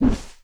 WHOOSH_Miss_mono.wav